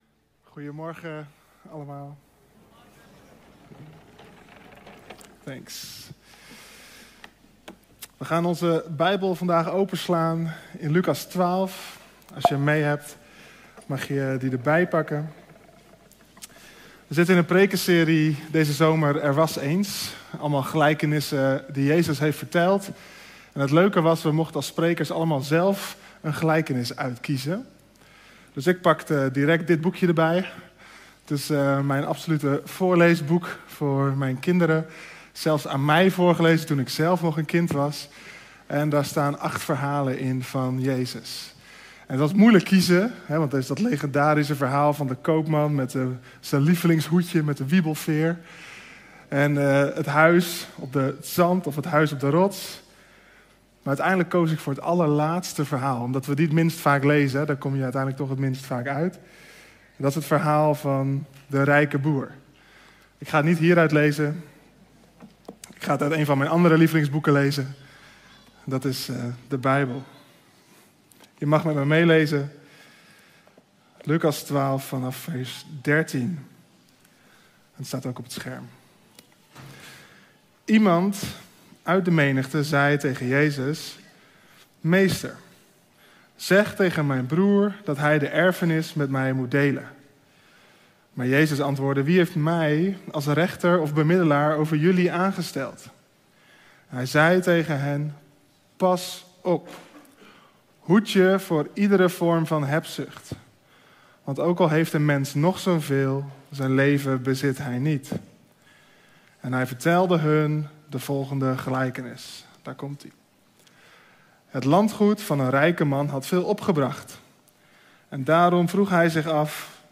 Heb je de preek gemist?